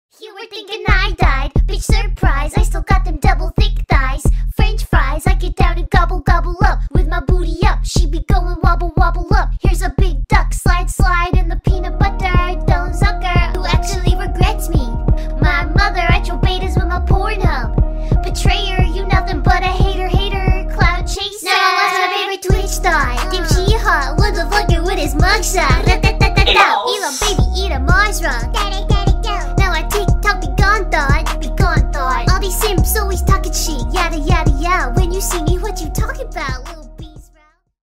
кавер